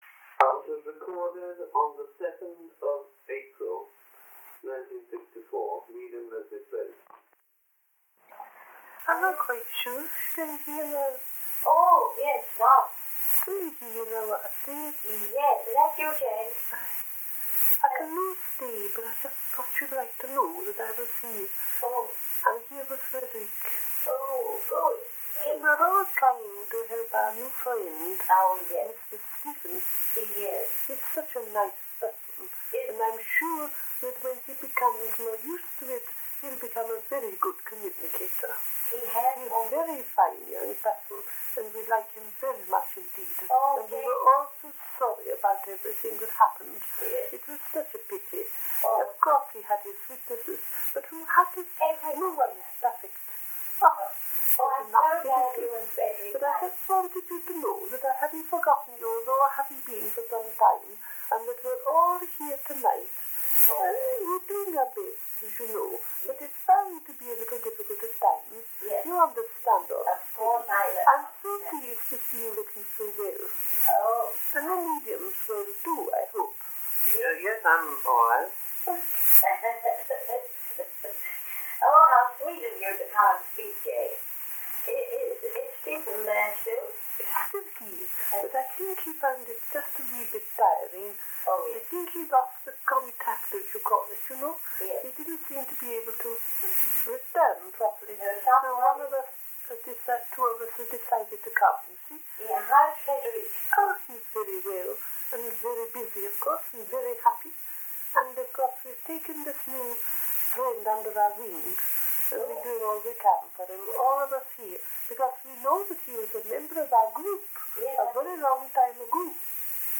Please note: Due to the age of the recordings (made over 30-70 years ago on equipment considered antique today), some may have background noise or be difficult to understand at times.